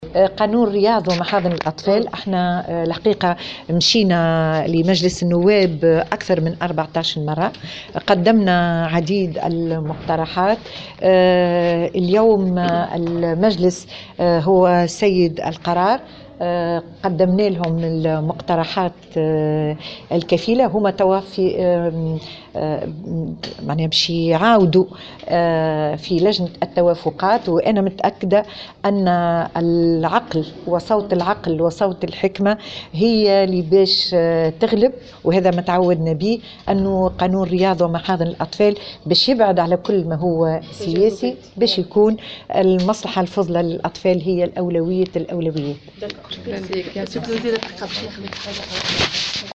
أكدت وزيرة المرأة والأسرة والطفولة وكبار السن نزيهة العبيدي،في تصريح لمراسلة الجوهرة "اف ام" اليوم الخميس 28 فيفري 2019 أن قانون رياض و محاضن الاطفال طرح على مجلس النواب أكثر من 14 مرة بعد تقديم عديد المقترحات في شأنه.
و أضافت الوزيرة على هامش ندوة صحفية 2019 أن البرلمان اليوم هو سيد القرار في ما يتعلق بهذا القانون بعد أن قدمت لهم كافة المقترحات الكفيلة.